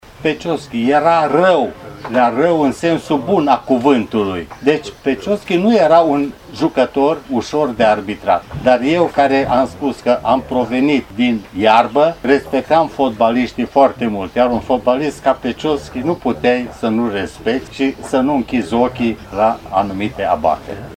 Volumul „Ioan Igna – între Petschovsky și Maradona”, semnat de Ionel Costin și Radu Romanescu a fost lansat, astăzi, la Arad, chiar în prezența fostului jucător și arbitru, care a împlinit, recent, 80 de ani, dar și a unor jucători importanți din istoria Bătrânei Doamne.
Ioan Igna a vorbit despre cum era Petchovsky ca jucător, din perspectiva unui cavaler al fluierului: